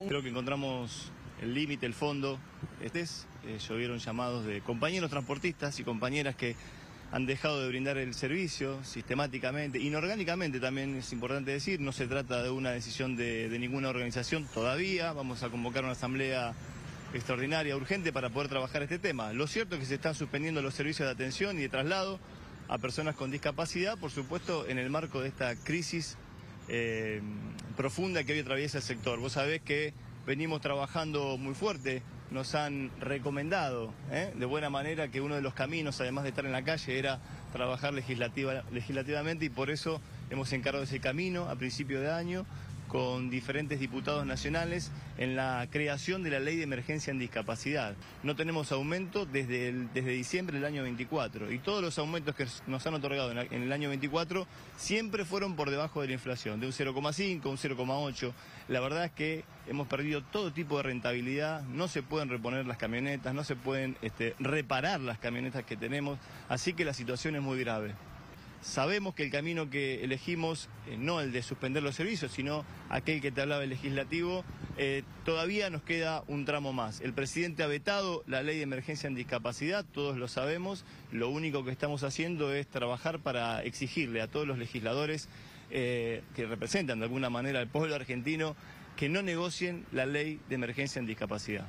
habló con el móvil de LT3